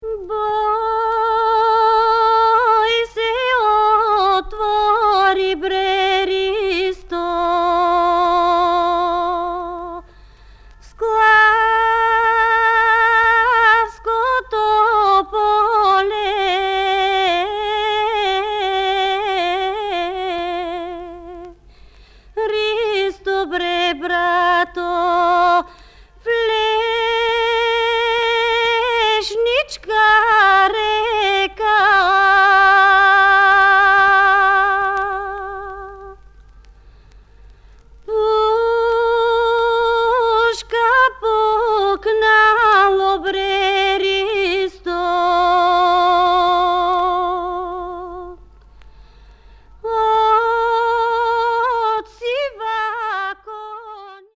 憂色を帯びたしなやかな声を聴かせる土着民謡や、素朴な響きに満ちた民族楽器の調べなど、生活の中の庶民的な音楽風景を真空パック。
キーワード：現地録り　ブルガリア 　バルカン